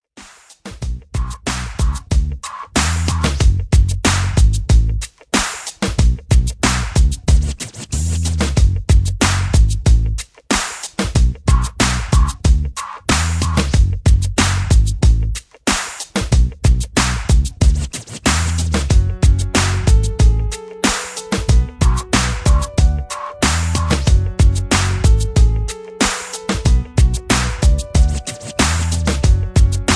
backing tracks
hip hop, r and b, rap